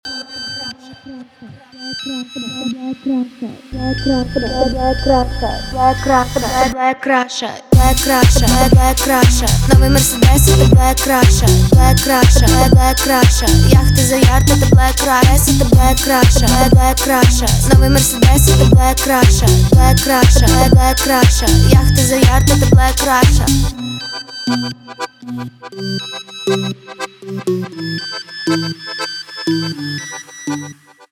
поп
битовые , качающие
нарастающие , аккордеон